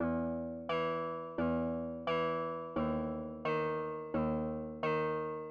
描述：合成器说唱
Tag: 87 bpm Hip Hop Loops Synth Loops 950.47 KB wav Key : Unknown FL Studio